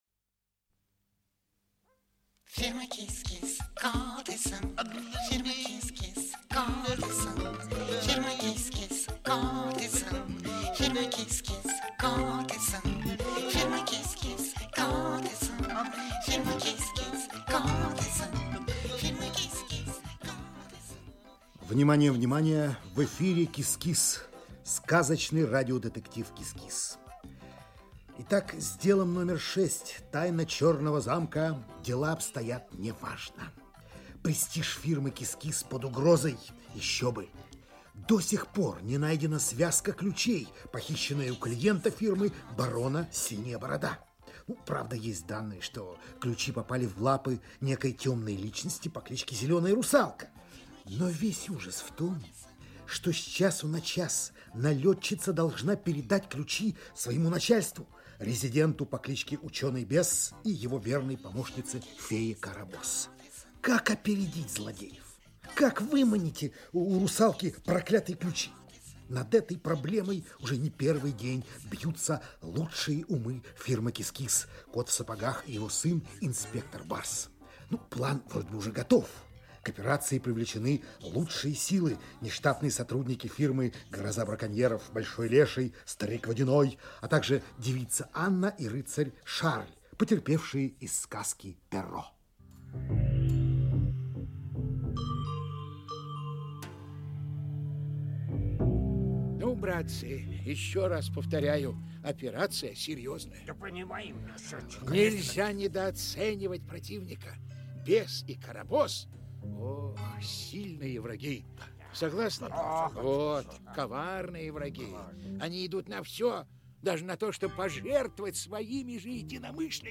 Аудиокнига КИС-КИС. Дело № 6. "Тайна Черного Замка". Часть 4 | Библиотека аудиокниг